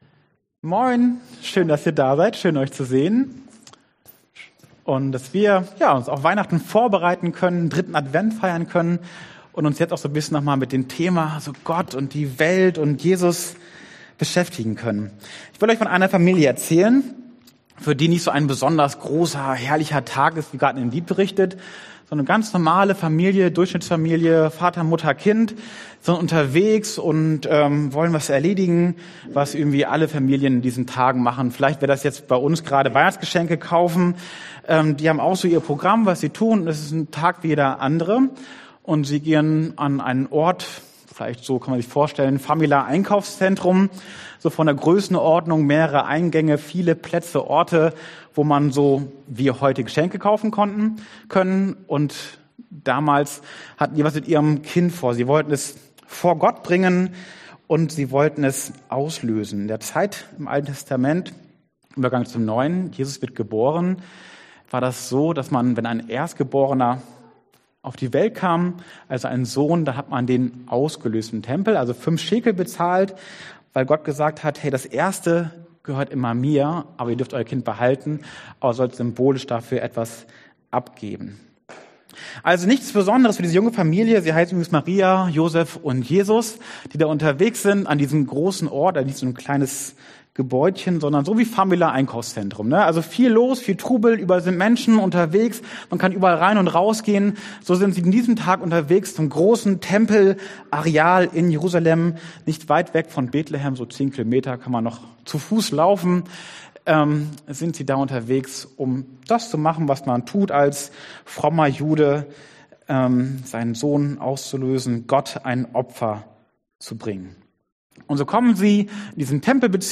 Bei Minute 31 kommt ein Poetry Slam, der nicht auf der Aufnahme ist.
Lukas 2,25-38 Dienstart: Predigt Bei Minute 31 kommt ein Poetry Slam